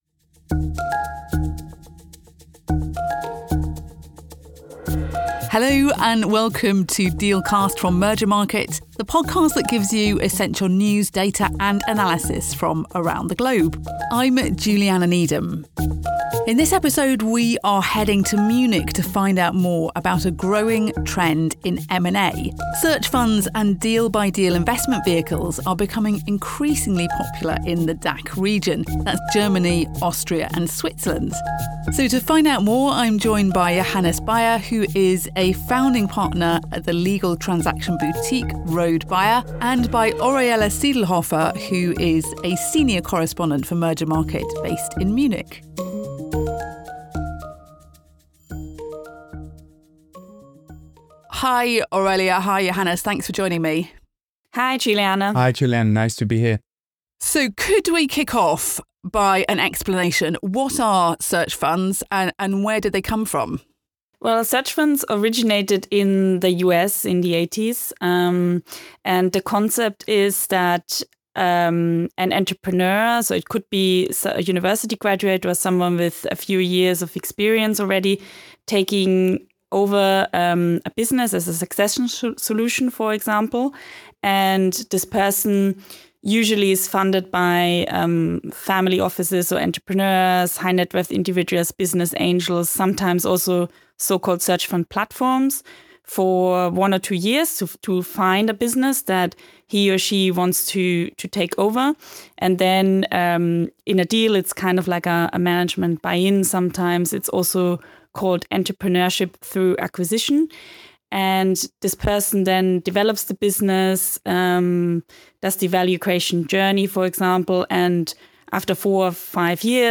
1 September 8, 2025 - A U.S. Sovereign Wealth Fund is a Bad Idea (Live Recording at John Wright Restaurant) 20:06